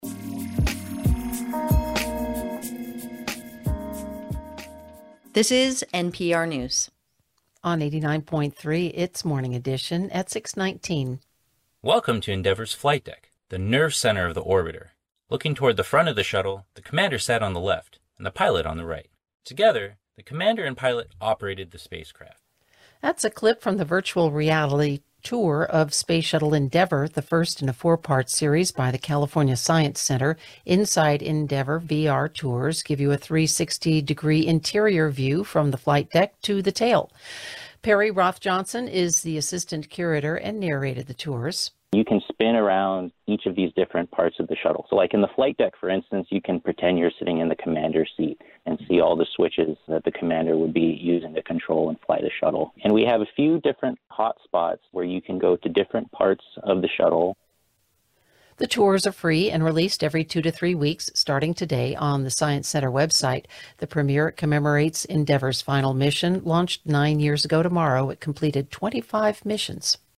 • Interview for ‘Inside Endeavour’ Tour (2020 May 15) KPCC 89.3 FM/NPR Morning Edition [